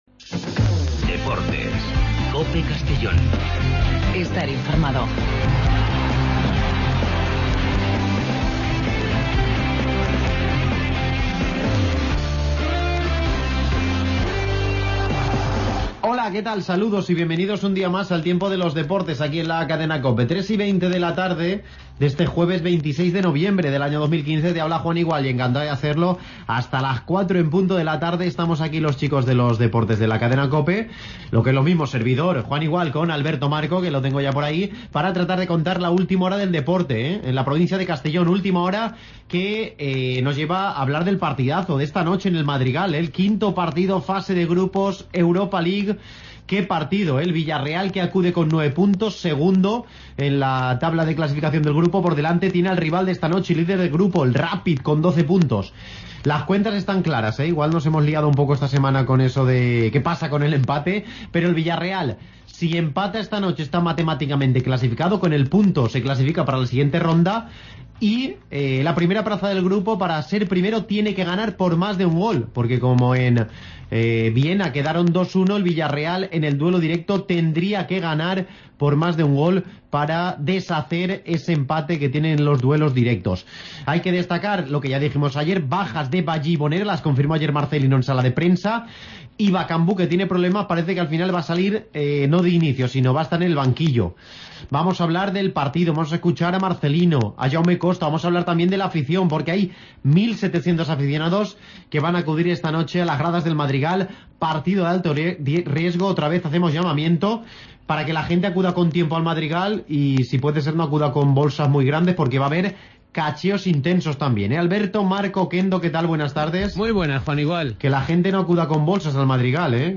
Programa deportivo donde conocemos la última hora de los equipos y deportistas de nuestra provincia.